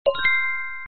audio_enter_room.mp3